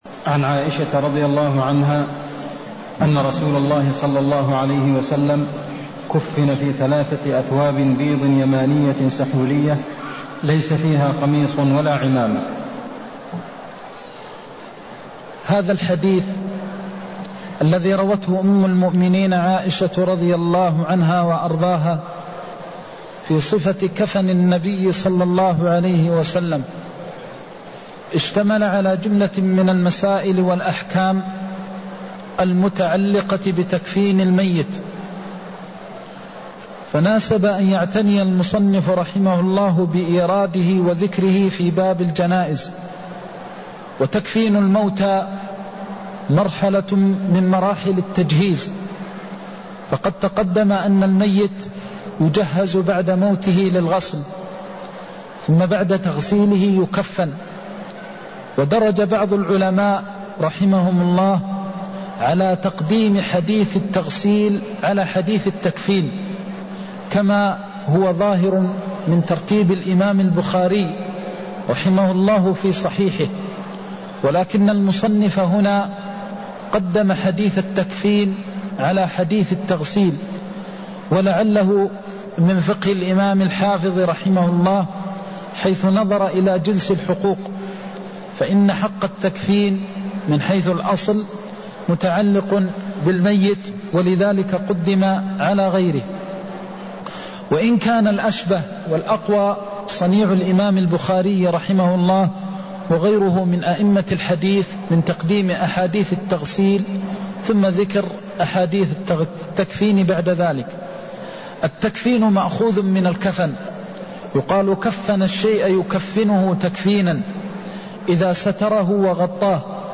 المكان: المسجد النبوي الشيخ: فضيلة الشيخ د. محمد بن محمد المختار فضيلة الشيخ د. محمد بن محمد المختار صفة تكفين النبي صلى الله عليه وسلم (152)